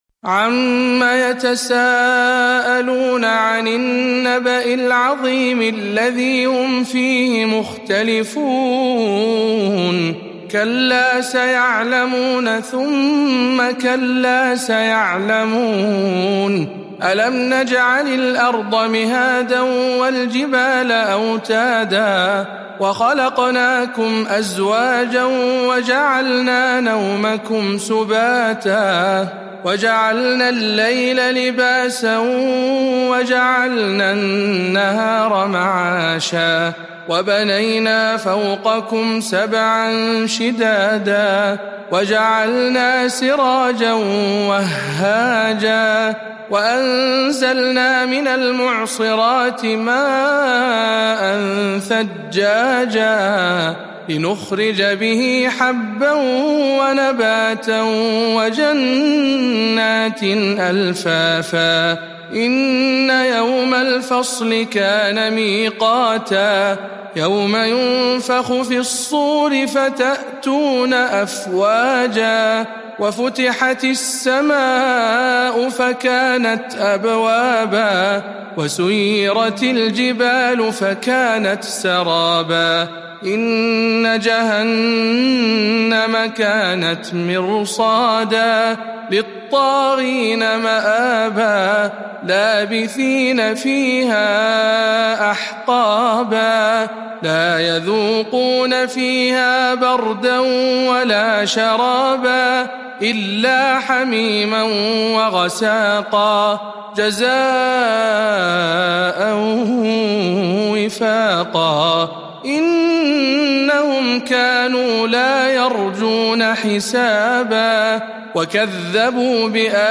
سورة النبأ برواية شعبة عن عاصم